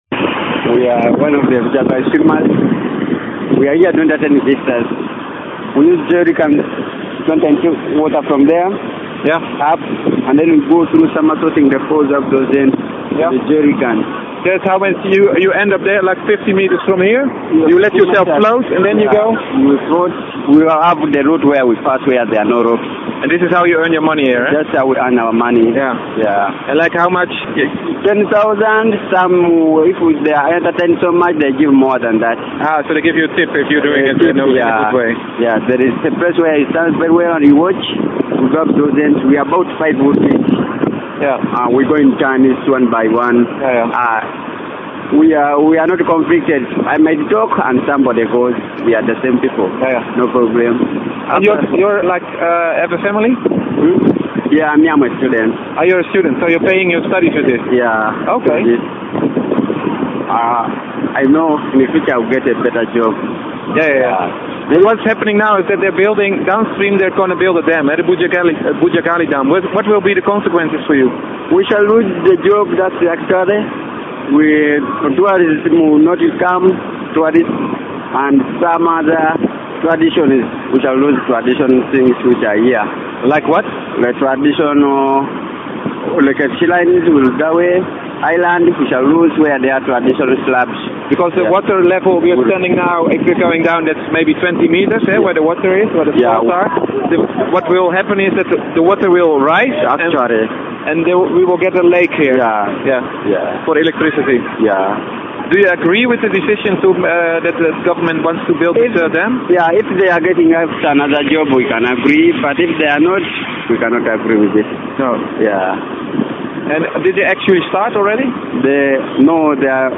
Listen to Mobile Phone Made Interview by clicking here.